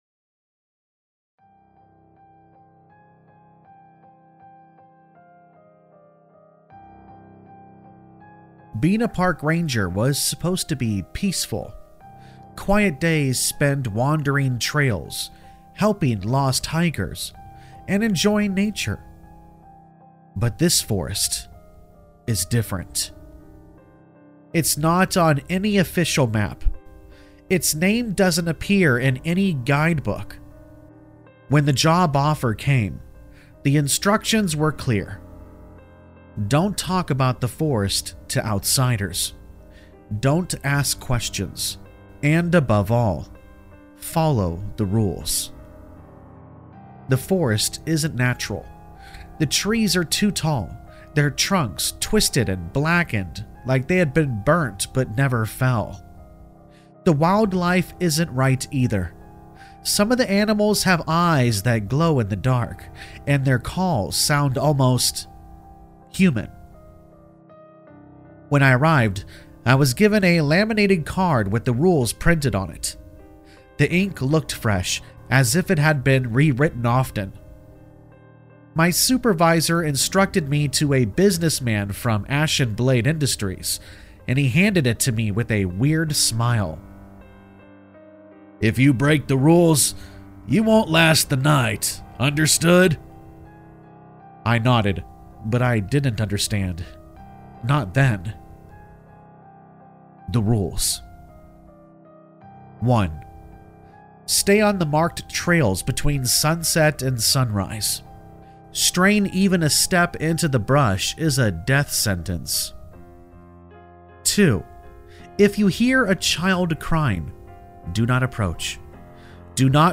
All Stories are read with full permission from the authors:
Campfire Tales is a channel that is human voiced that does NOT use a fake Ai voiced simulator program.